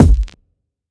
Kick MadFlavor 6.wav